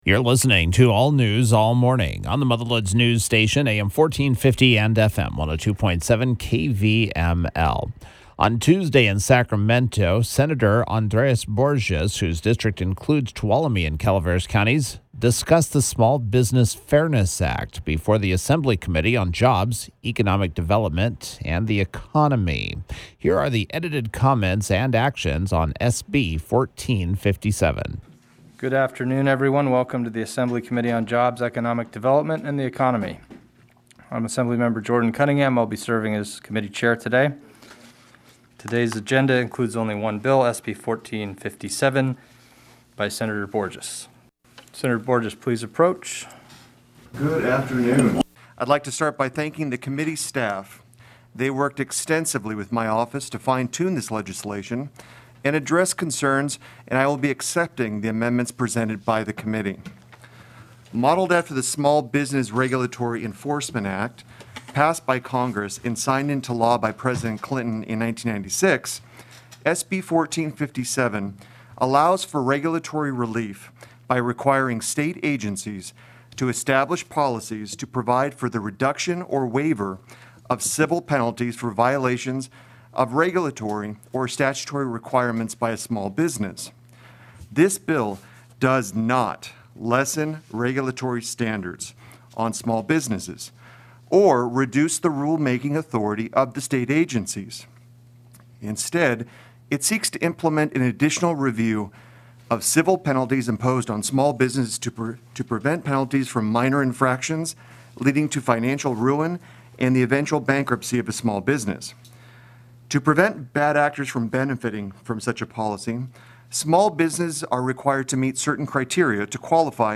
Borgeas was Thursday’s KVML “Newsmaker of the Day”.